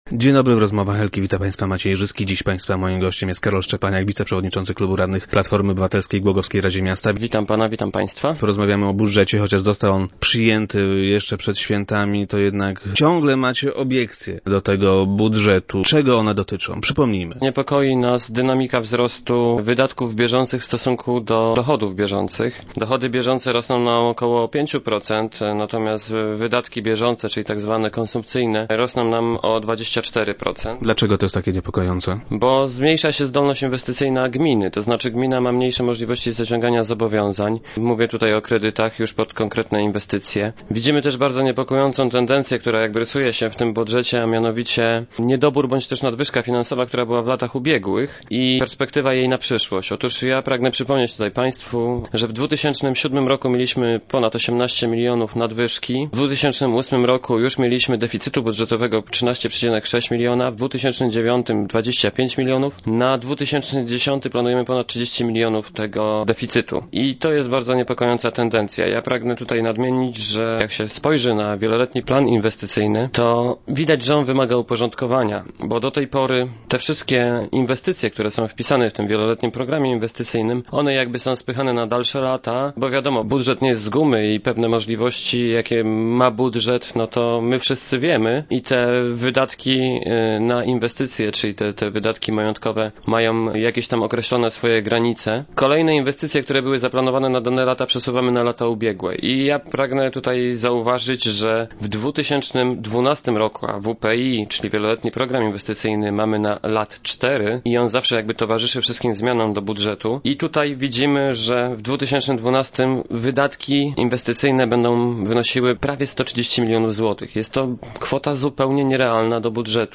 Uważamy jednak, że budżet jest niedoskonały, że jest to budżet przetrwania i na dodatek jest on mocno socjalistyczny - powiedział na radiowej antenie radny Szczepaniak.